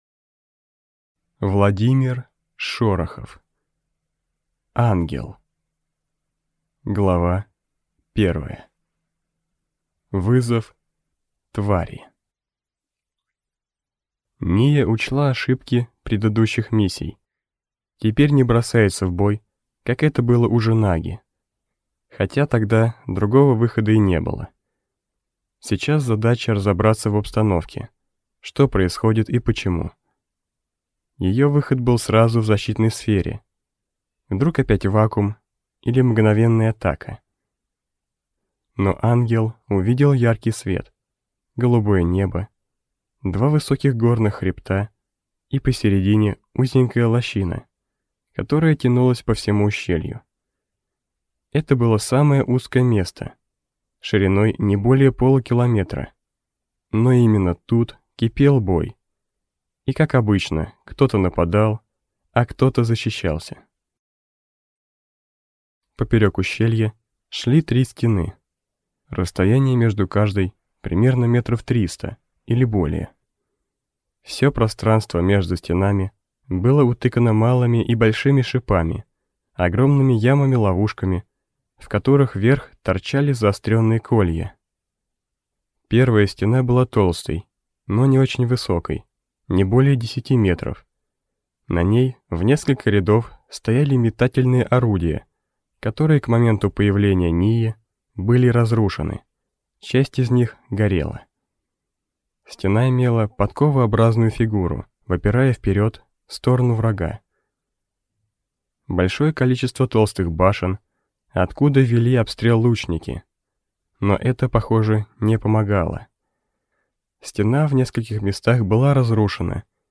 Аудиокнига Ангел | Библиотека аудиокниг